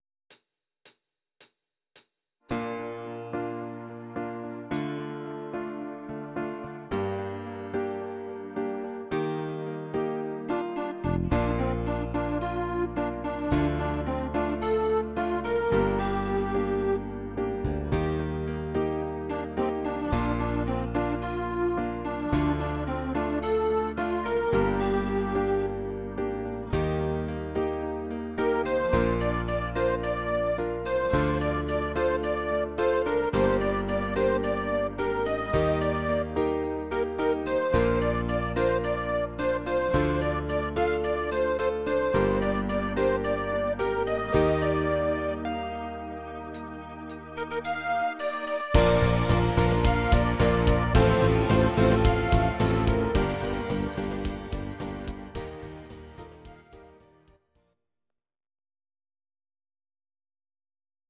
Audio Recordings based on Midi-files
Pop, Musical/Film/TV, 2010s